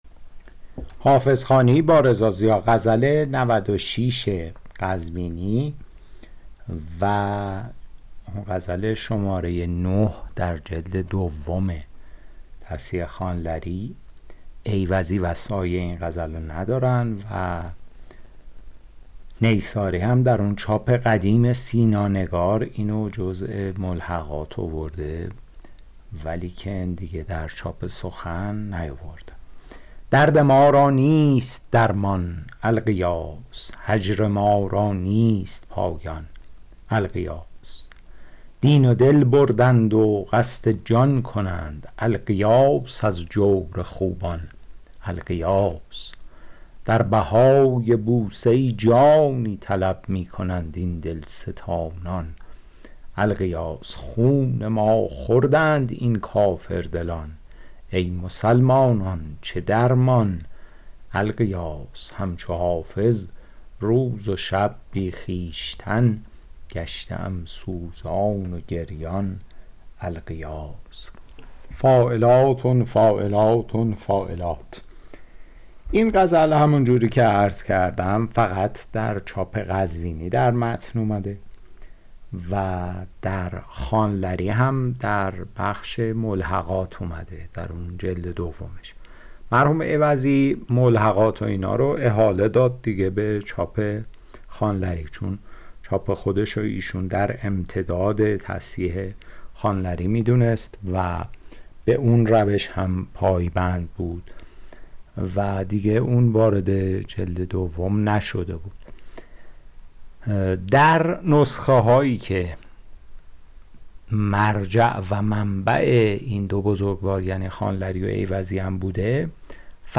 شرح صوتی غزل شمارهٔ ۹۶